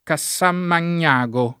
Cassanmagnago [ ka SS amman’n’ #g o ]